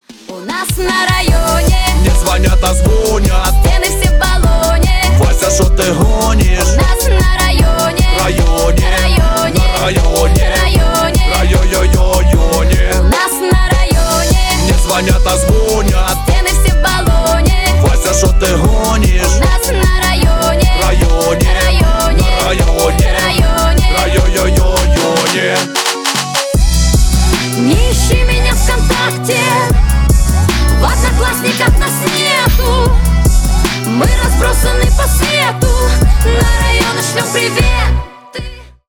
• Качество: 320 kbps, Stereo
Ремикс
Поп Музыка
весёлые